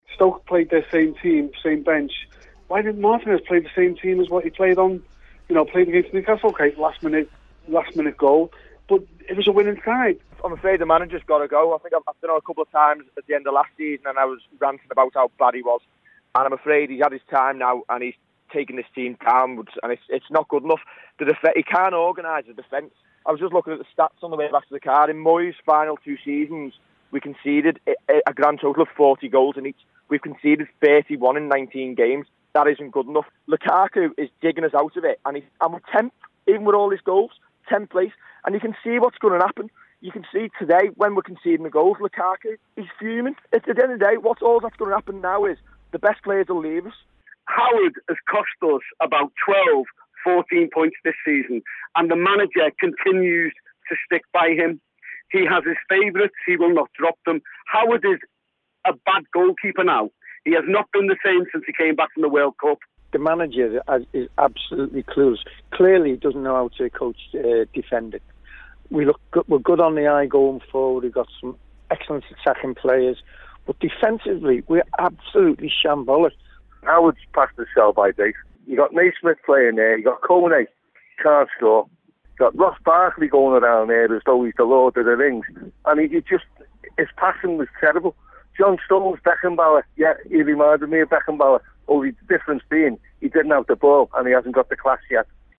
Callers after Everton's defeat to Stoke criticise the Blues' goalkeeper and manager.